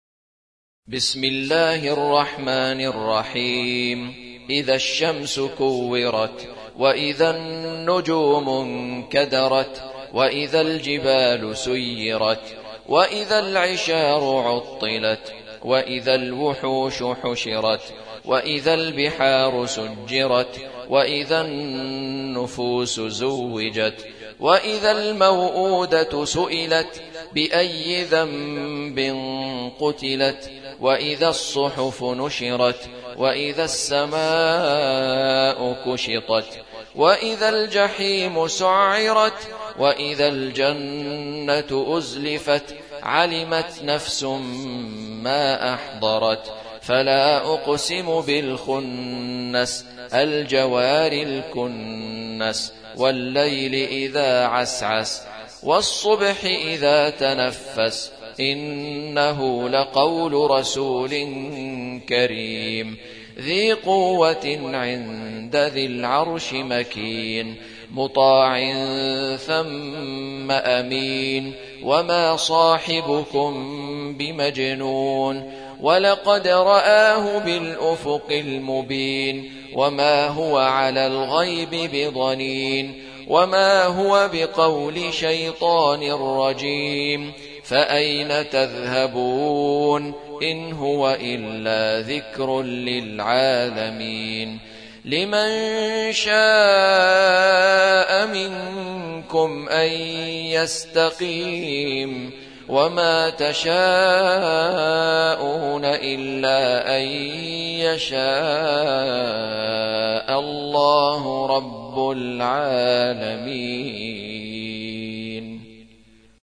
Surah Sequence تتابع السورة Download Surah حمّل السورة Reciting Murattalah Audio for 81. Surah At-Takw�r سورة التكوير N.B *Surah Includes Al-Basmalah Reciters Sequents تتابع التلاوات Reciters Repeats تكرار التلاوات